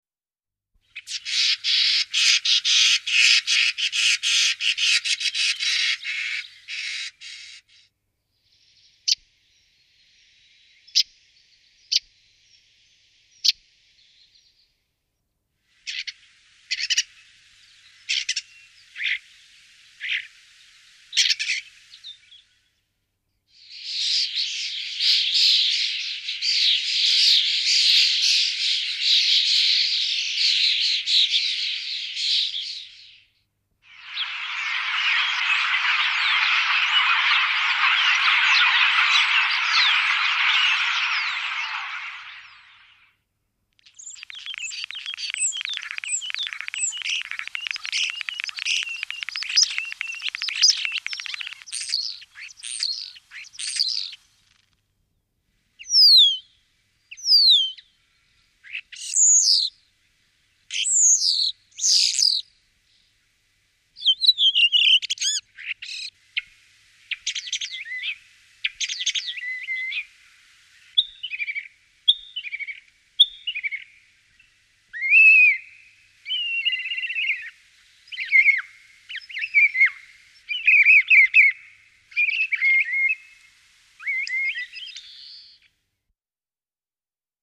Скворец обыкновенный (Шпак звычайны)
скворец-обыкновенный-Шпак-звычайны.mp3